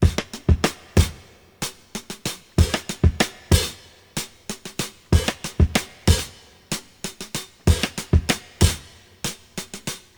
• 94 Bpm Drum Loop E Key.wav
Free drum loop sample - kick tuned to the E note.
94-bpm-drum-loop-e-key-DZK.wav